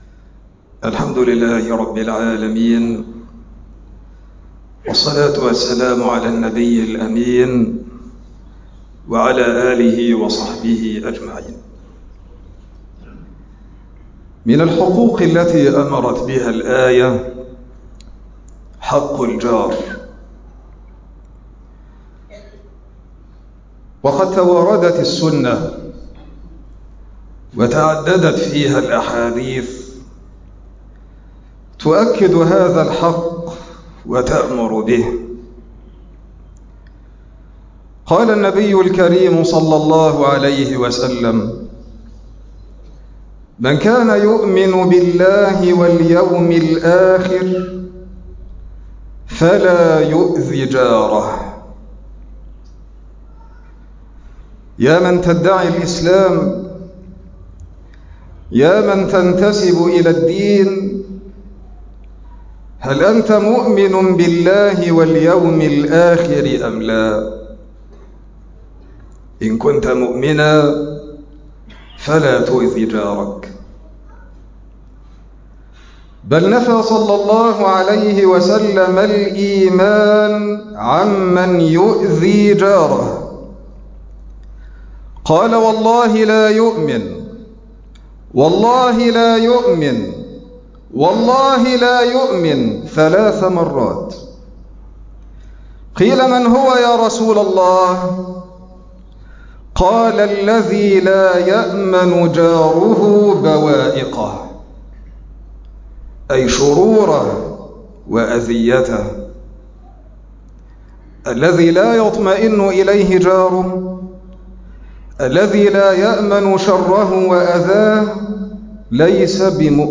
مقطع من خطبة الجمعة